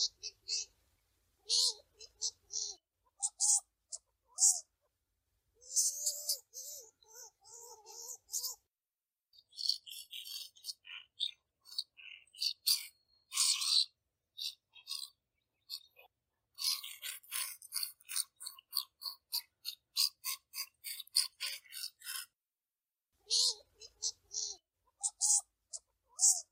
Звуки скунса
Звуки общения скунсов